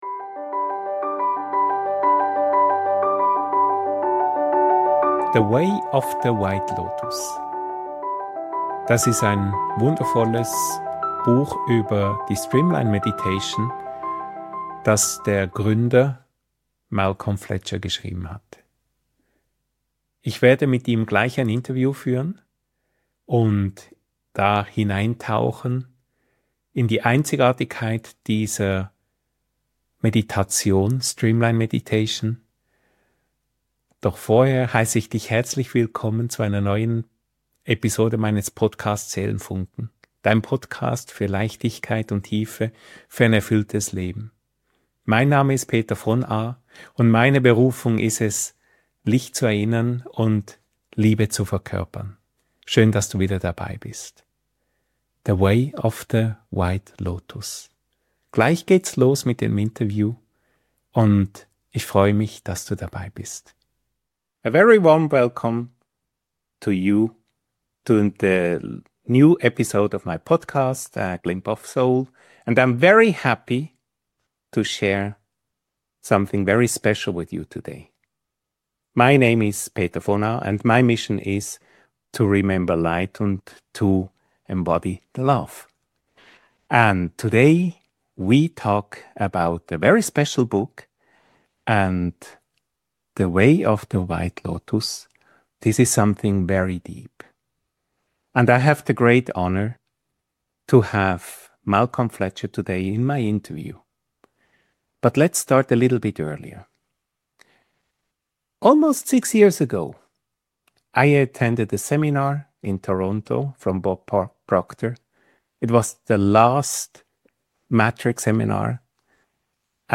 The Way of the White Lotus - Interview